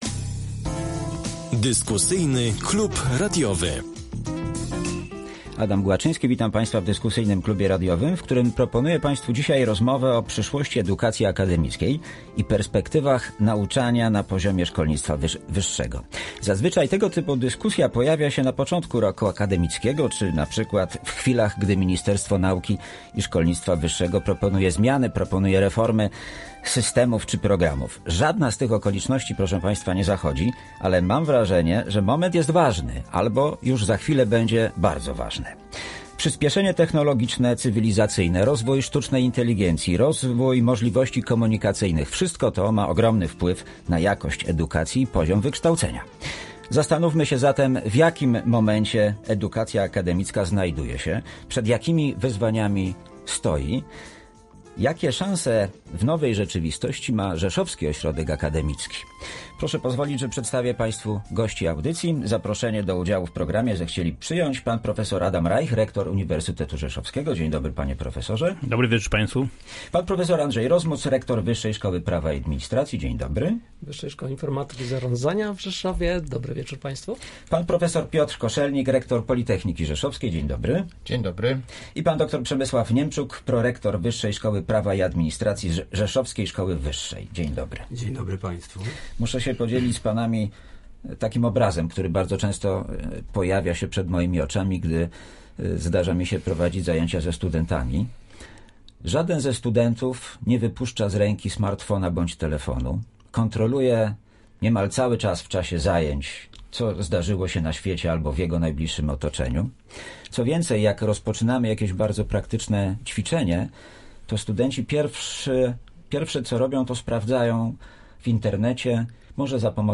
W Dyskusyjnym Klubie Radiowym rozmowa o przyszłości edukacji akademickiej i perspektywach nauczania na poziomie szkolnictwa wyższego.